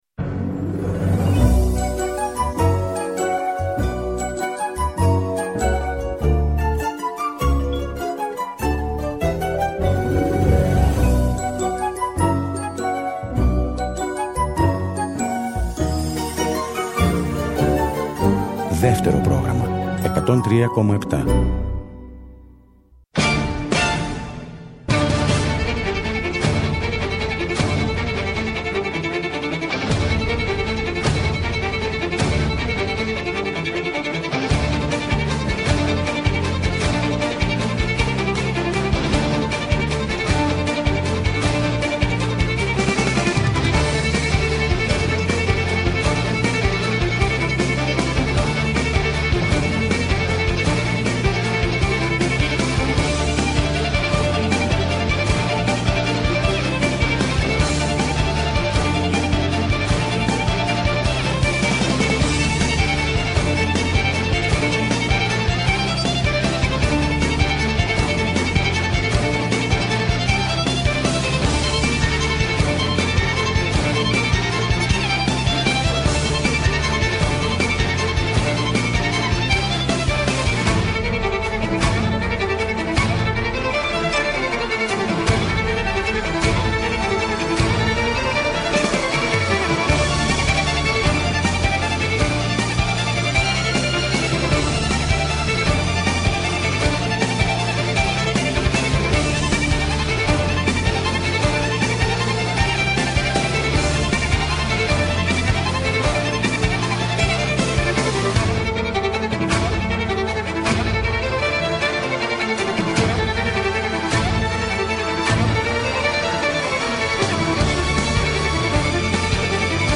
Live στο Studio Μουσική Συνεντεύξεις